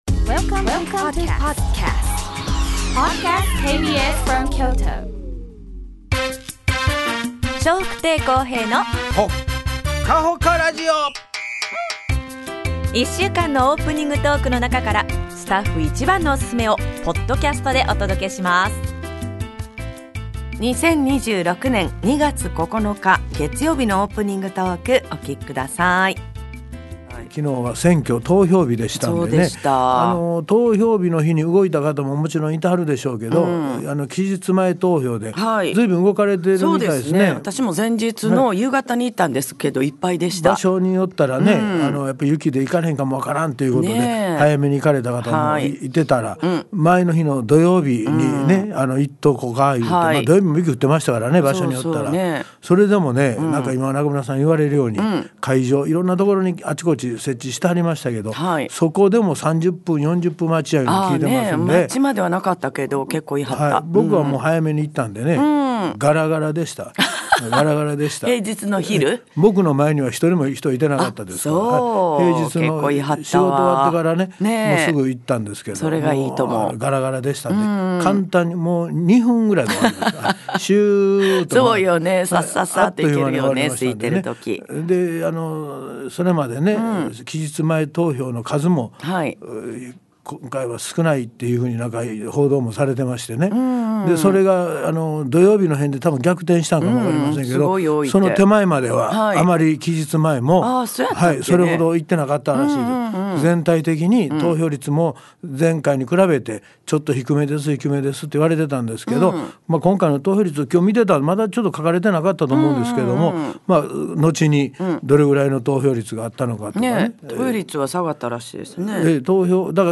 2026年2月9日のオープニングトーク